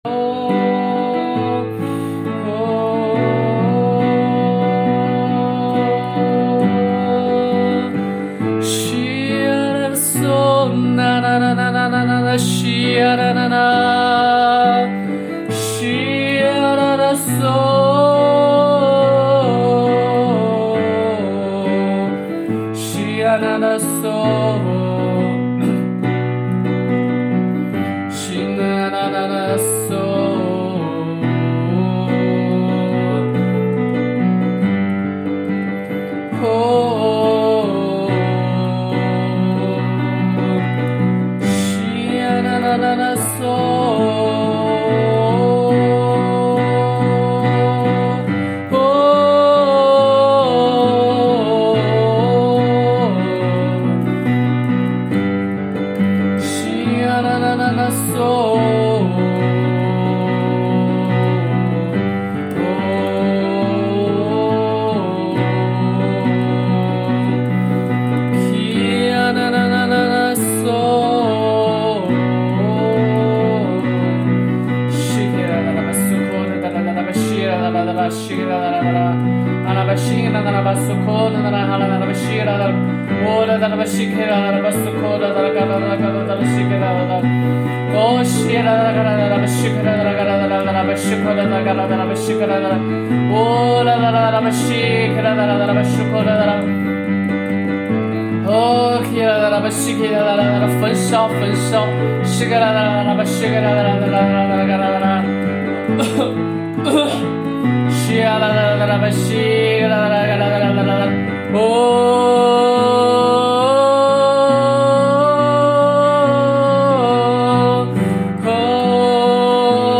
HAKA祷告敬拜MP3 启示性祷告： 持续祷告：祈求神的旨意成就在我们的身上，带领做新事！